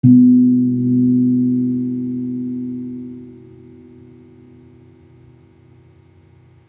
Gong-B2-p.wav